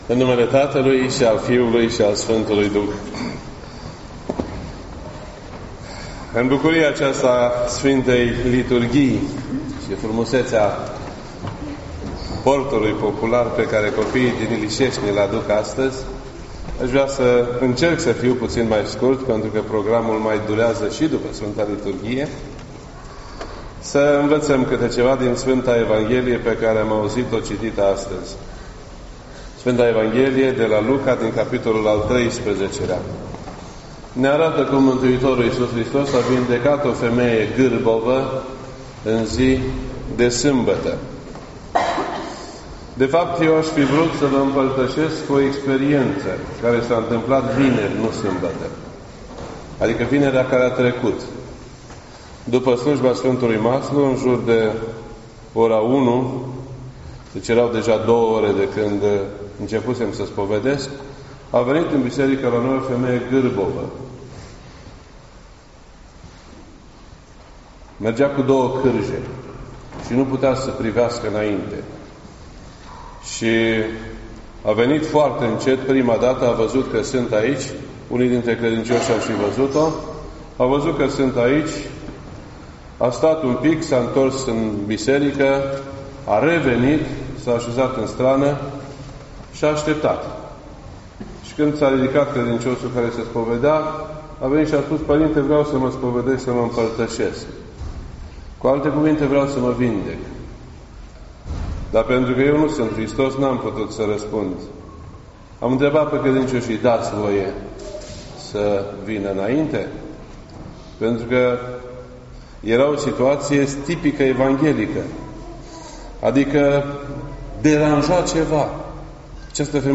This entry was posted on Sunday, December 10th, 2017 at 1:50 PM and is filed under Predici ortodoxe in format audio.